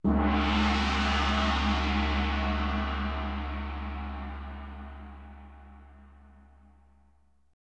GONG